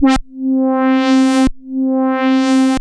Roland A C4.wav